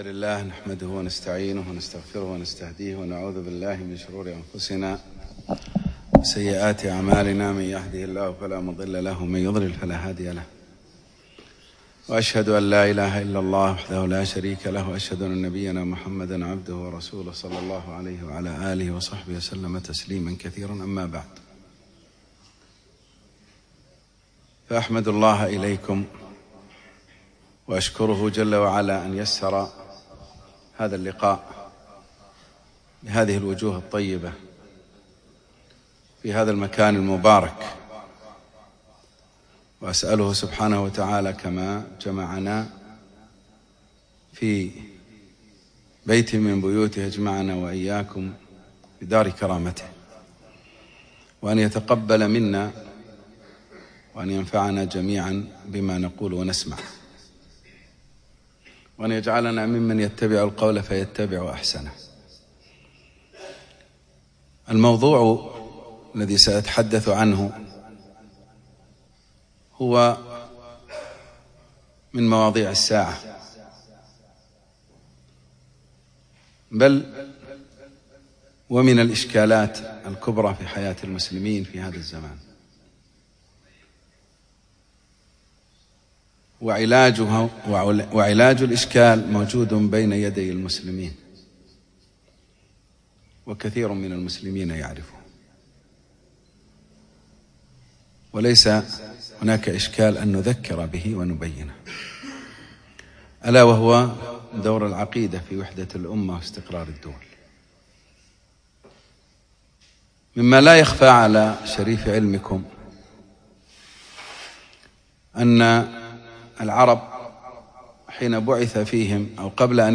يوم الأربعاء 28 جمادى الأخر 1437 الموافق 6 4 2016 في مسجد كليب مضحي العارضية